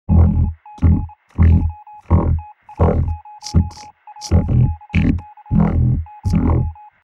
NUMBERS 0 - 9 (ROBOTIC VOICE)
A robotic voice saying Numbers between zero to nine.
The morse background noise is NOT part of the files!
RobotNumbers-preview.mp3